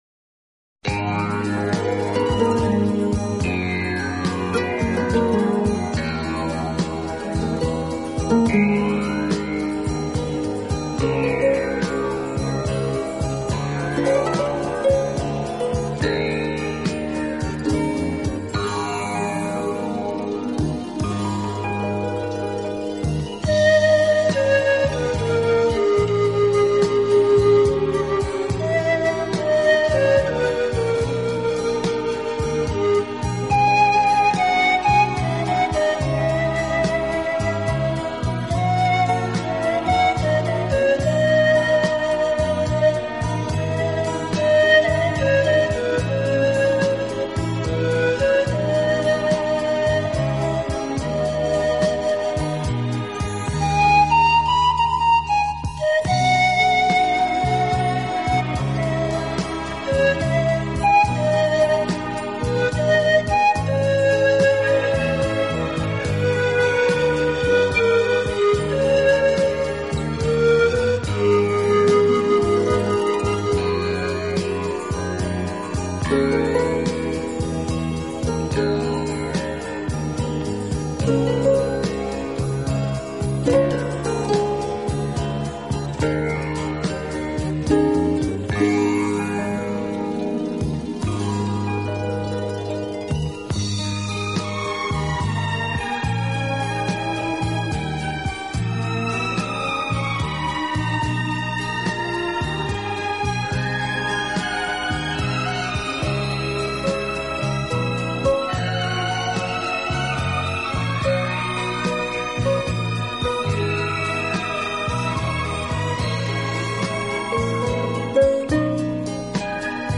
置身于世外桃园，尽情享受这天簌之音……